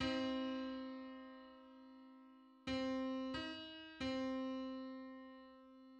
File:Six-hundred-twenty-first harmonic on C.mid - Wikimedia Commons
Public domain Public domain false false This media depicts a musical interval outside of a specific musical context.
Six-hundred-twenty-first_harmonic_on_C.mid.mp3